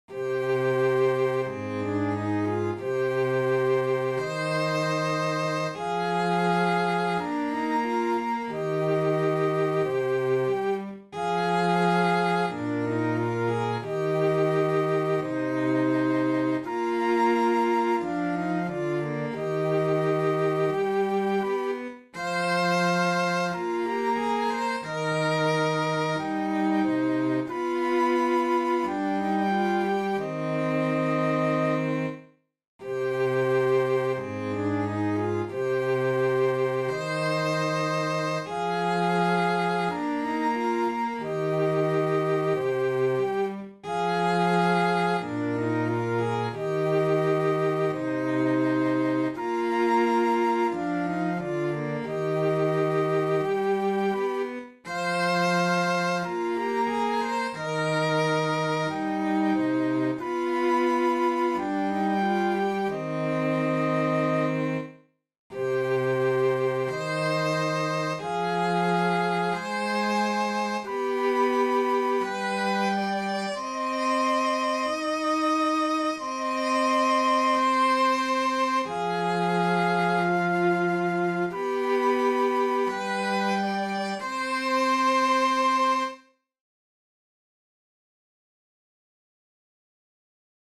Vapahtajan-huomaan-sellot-ja-huilu.mp3